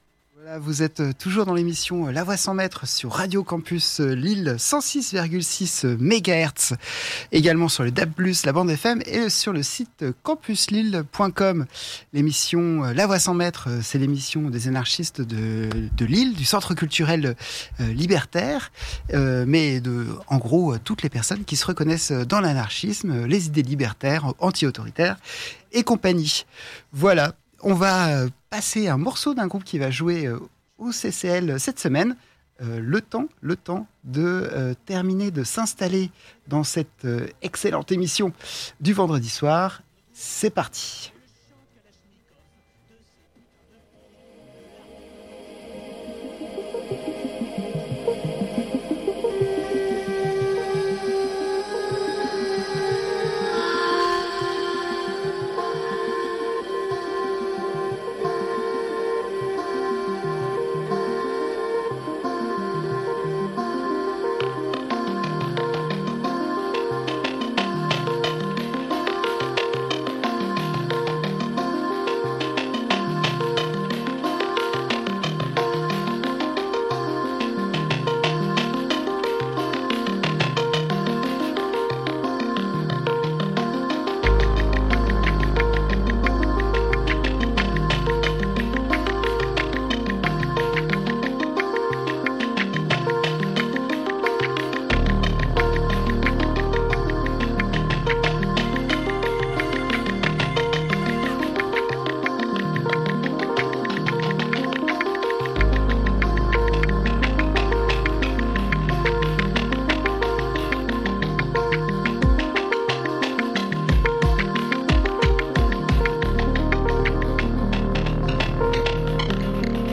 Vous pouvez l’écouter ici, on commence la discussion à 28 minutes, à la fin de l’agenda : [La Voix Sans Maitre 16 mars 2025 ]